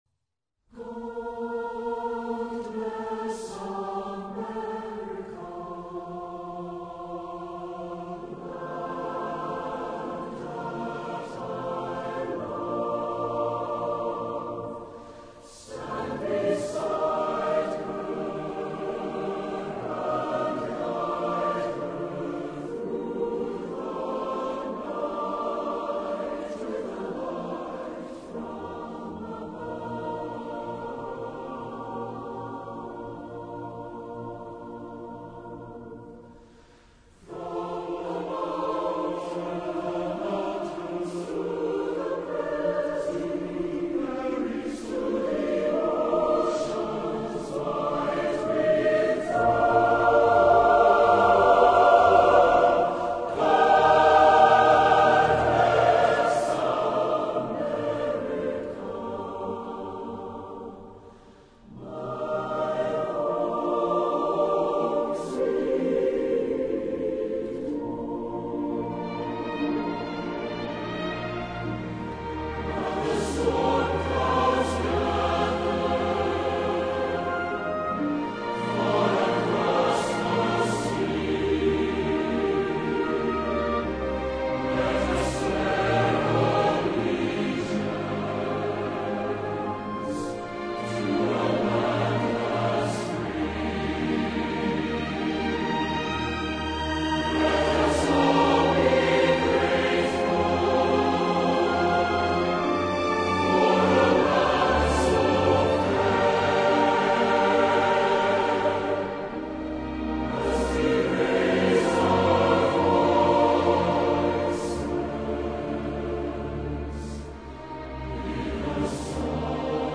patriotic tune